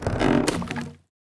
BridgeClose.wav